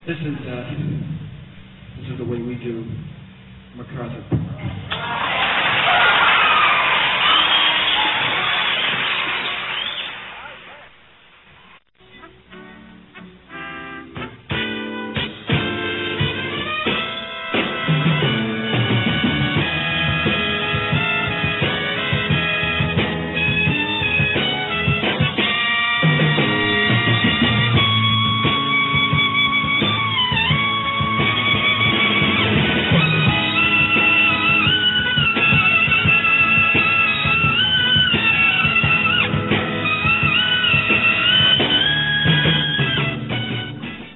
Non-Album songs that are known to have been played live: